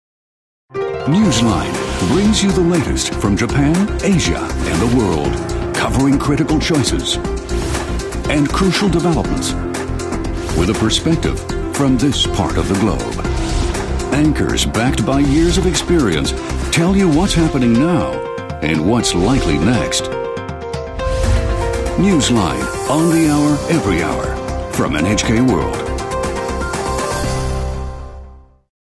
English inflection: Neutral North American
Tone: Baritone